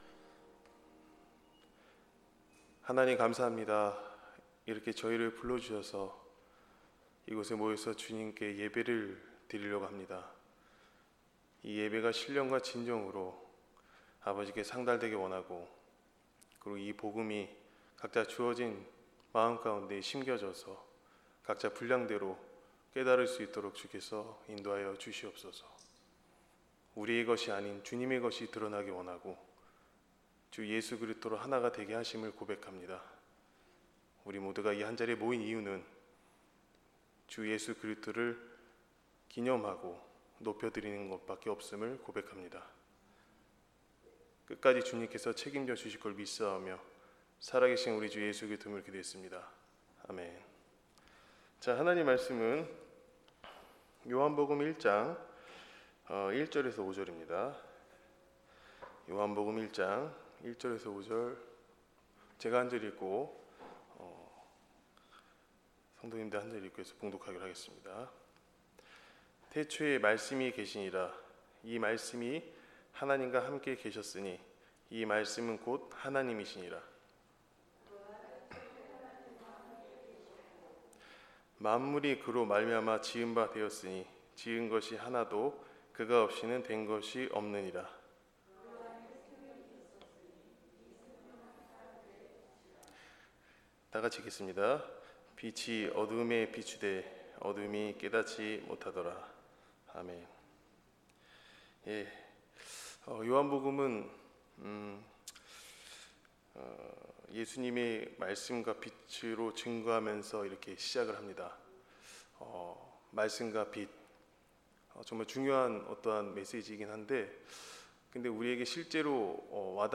수요예배 요한복음 1장 1~5절